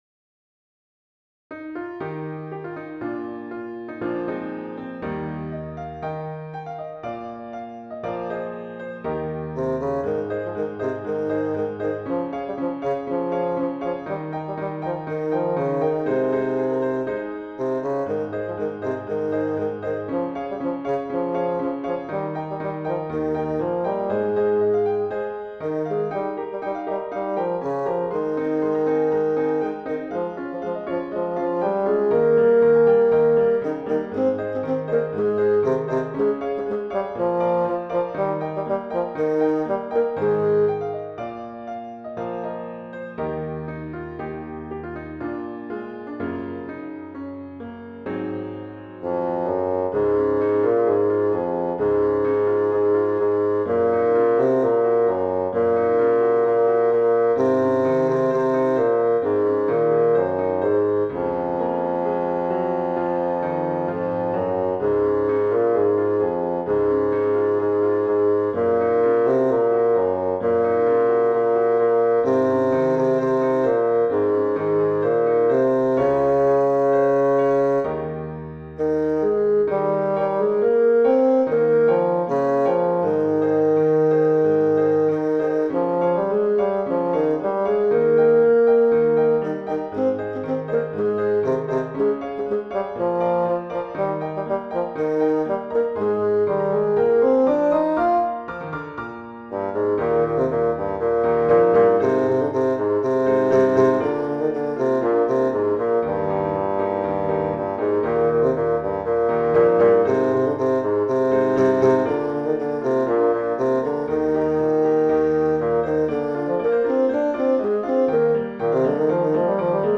Intermediate Instrumental Solo with Piano Accompaniment.
Christian, Gospel, Sacred.
set to a fast past, energetic jig.